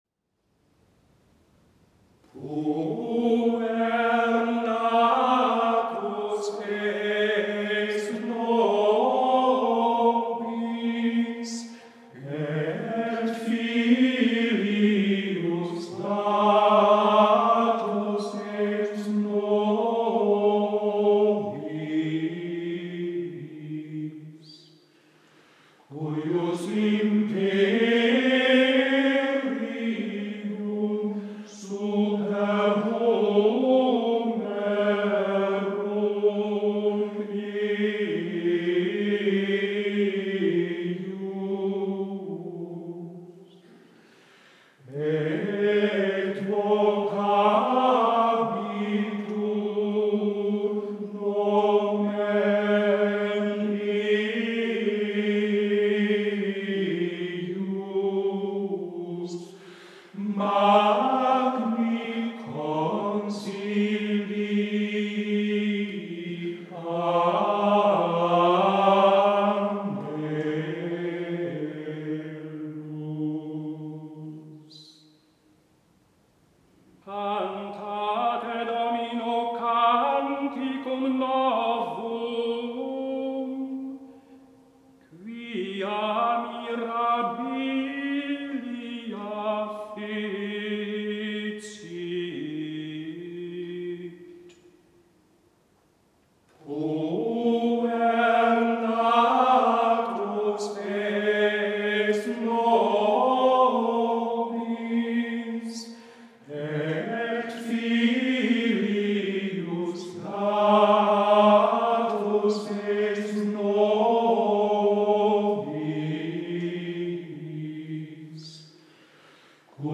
The first line of the antiphon is very conversational and seems to focus around one note.
This is particularly evident in the Psalm verse sung by the cantor.
The texture of the chant is also quite simple: there is no accompaniment to the singing and all voices sing the same melodic line.
Cantate Domino canticum novum... 1:18 Psalm Verse: (B) A soloist sings a more syllabic line of music.
Puer natus est... 1:37 Antiphon: (A)The choir reenters with the Antiphon.
Notum fecit Dominus... 2:50 Psalm Verse: (B') The soloist returns with a slightly varied version of the melody of the previous psalm verse.